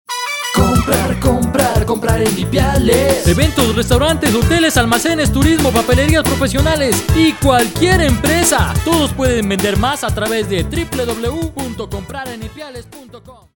Comerciales y Jingles para Radio